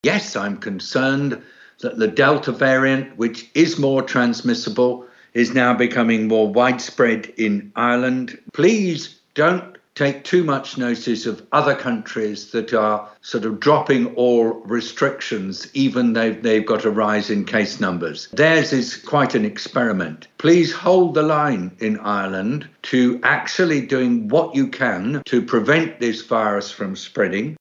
1,110 cases were confirmed yesterday – and Dr David Nabarro, from the World Health Organisation, says the situation’s worrying: